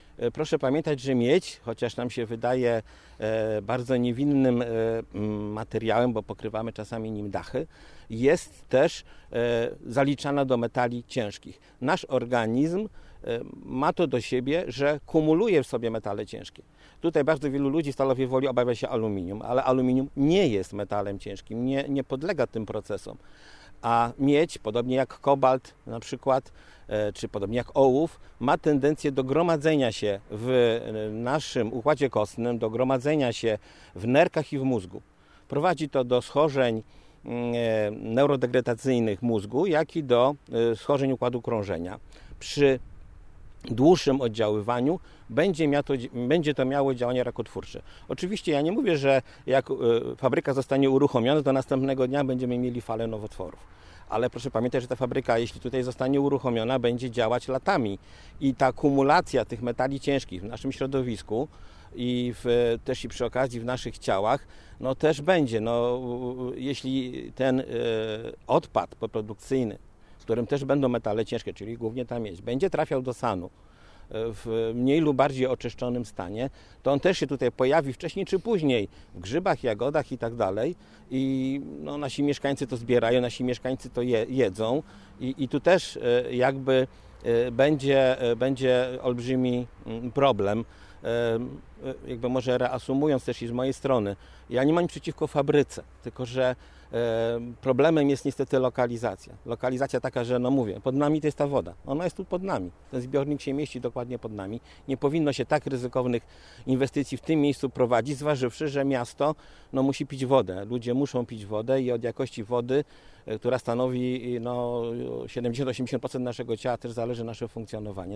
Podczas spotkania z mediami Andrzej Szlęzak podkreślił, że jedno z ujęć wody pitnej zostało już zatrute i zamknięte o czym się nie mówi.